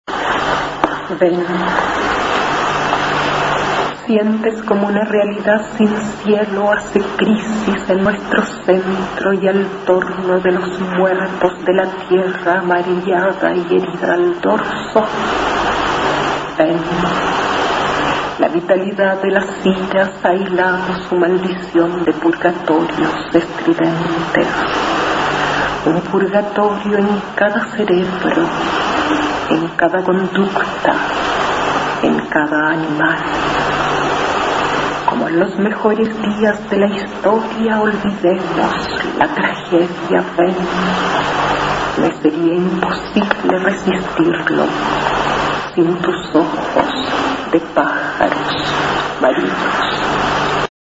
Aquí se puede escuchar a la autora nacional Astrid Fugellie recitando su poema Ven, del libro "Las jornadas del silencio" (1984).
Poetisa